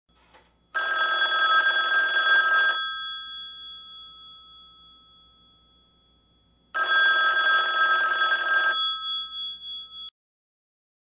Altes Amerikanisches Telefon Klingelton
Kategorie: Klingeltöne
Der Klingelton „Old Phone Ring Ring“ erinnert an die gute alte Zeit, als Telefone noch mit einem charakteristischen Klingeln aufwarteten.
altes-amerikanisches-telefon-klingelton-de-www_tiengdong_com.mp3